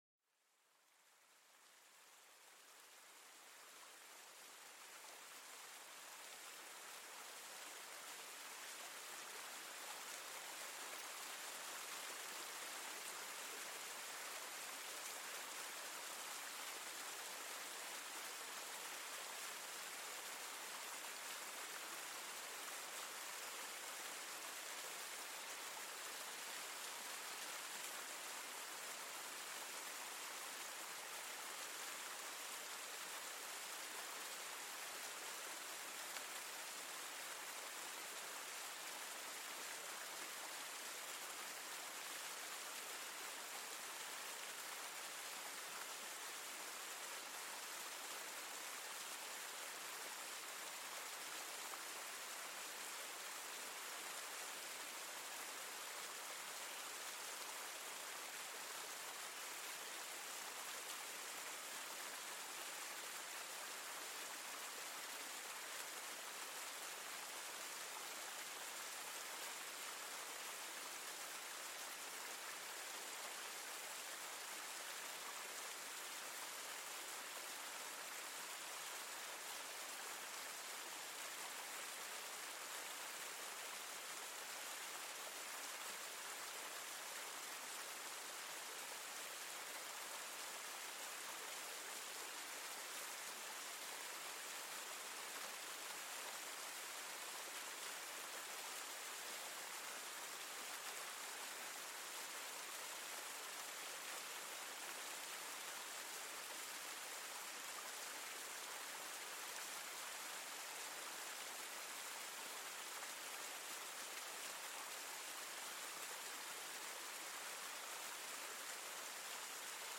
Serenidad Fluvial: Relájate con el sonido del agua corriente